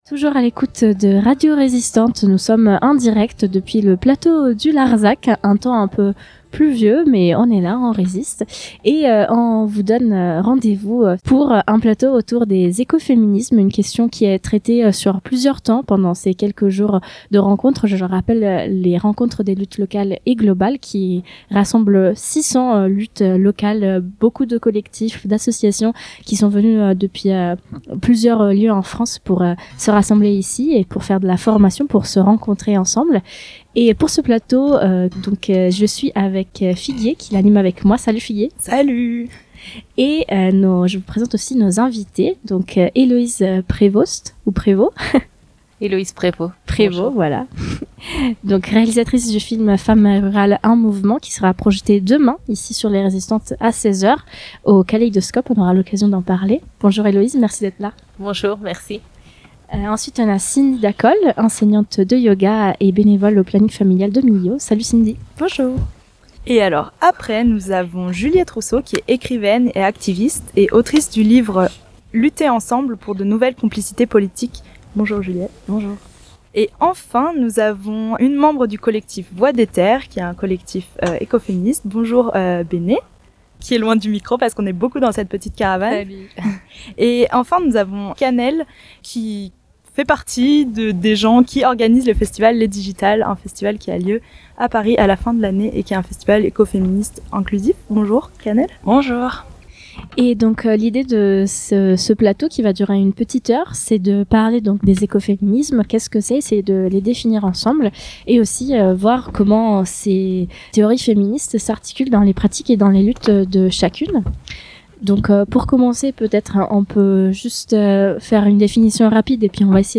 Retrouvez un plateau autour de l’éco-féminisme enregistré lors des Résistantes 2023, le vendredi 4 août.